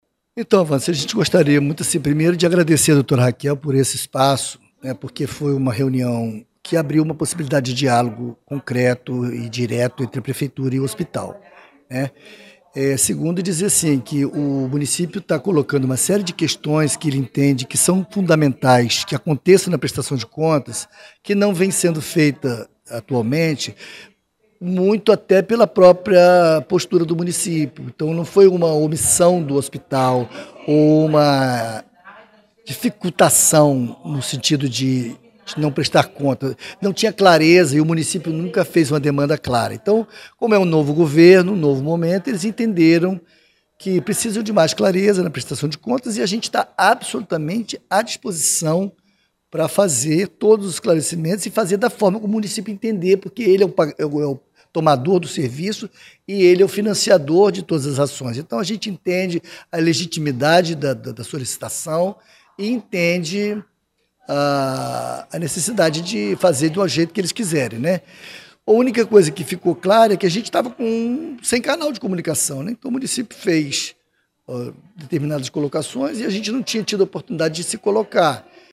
7 de julho de 2025 ENTREVISTAS, NATIVIDADE AGORA